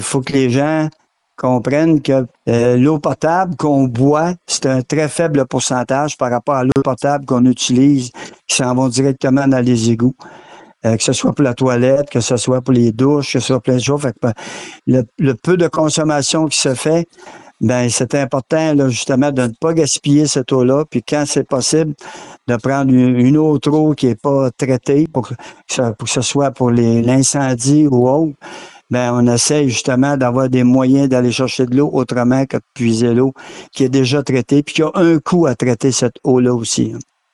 En entrevue, le maire a rappelé l’importance de faire attention à cette richesse naturelle.